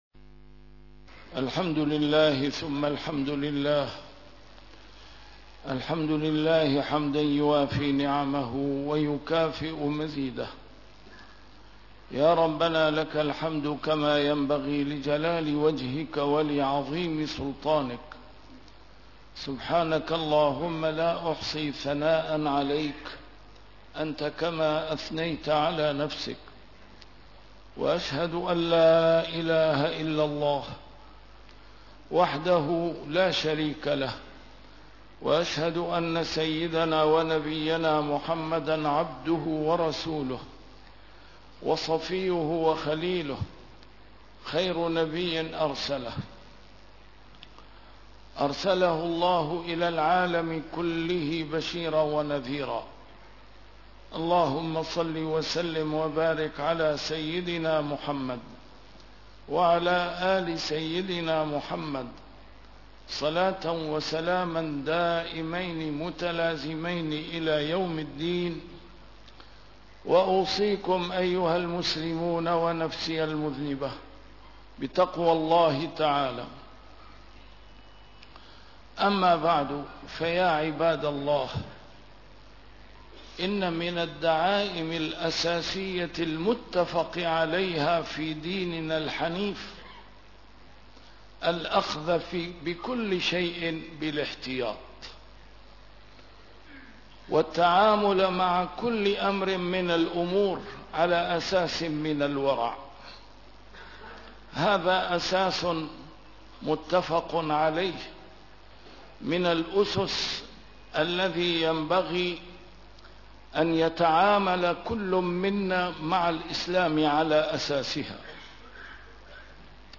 A MARTYR SCHOLAR: IMAM MUHAMMAD SAEED RAMADAN AL-BOUTI - الخطب - من أسس الدين الورع